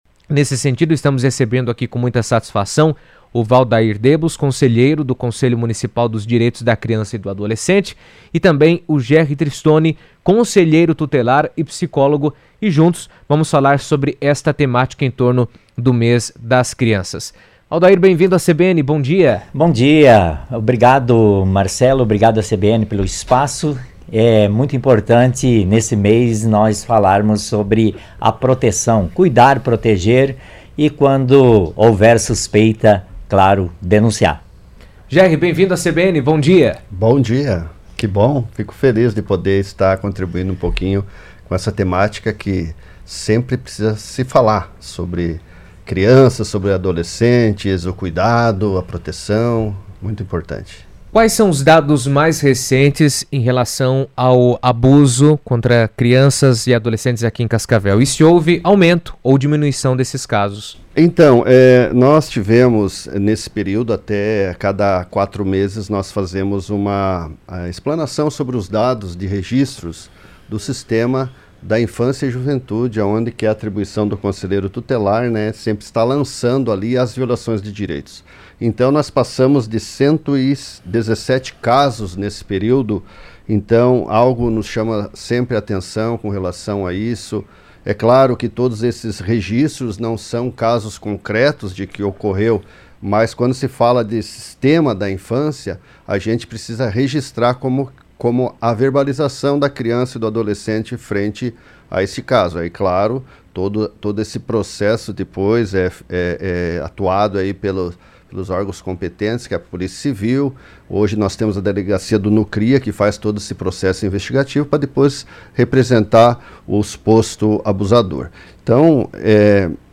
estiveram na CBN destacando a importância de políticas de proteção, conscientização da sociedade e ações de prevenção para garantir o bem-estar e a segurança dos menores.